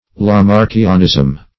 Lamarckianism \La*marck"i*an*ism\, n.
lamarckianism.mp3